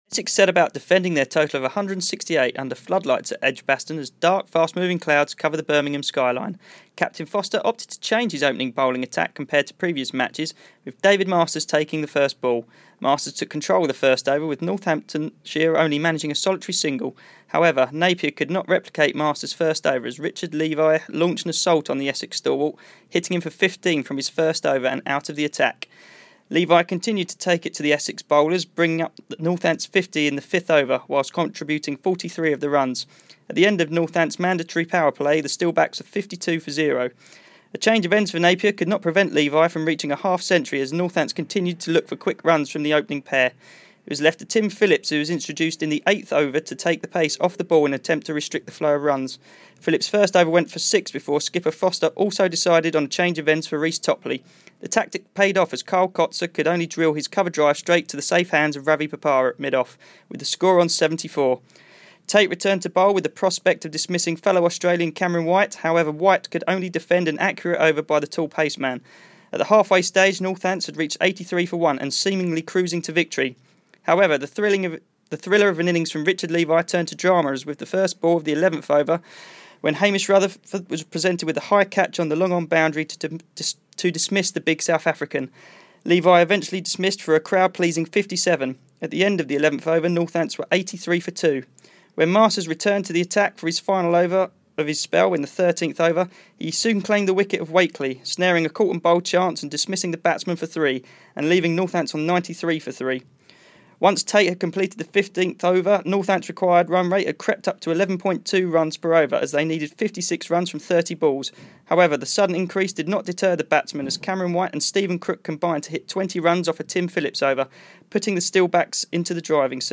Second innings match report from Edgbaston after the Eagles' defeat to the Steelbacks on Finals Day